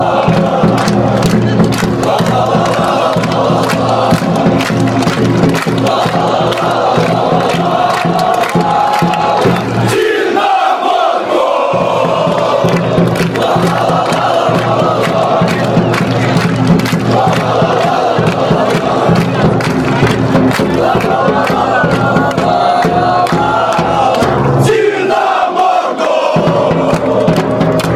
Классическая кричалка московского Динамо в хорошем качестве.